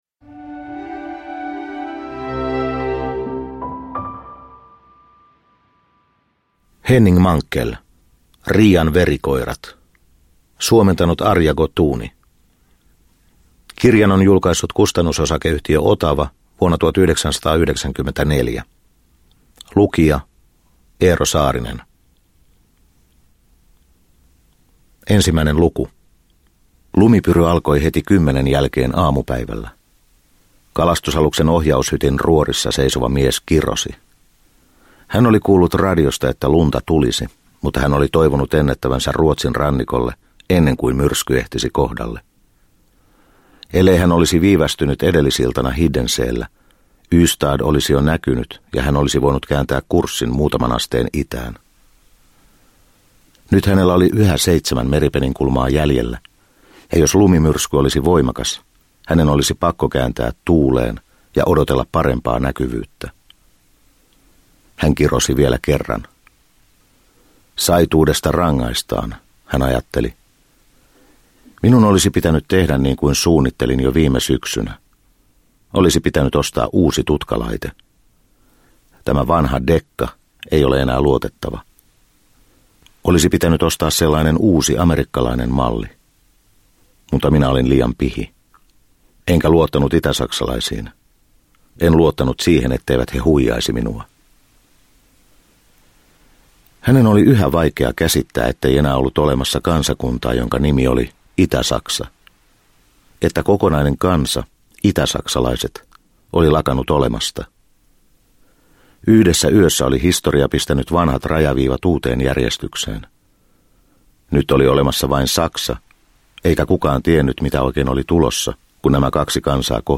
Riian verikoirat – Ljudbok – Laddas ner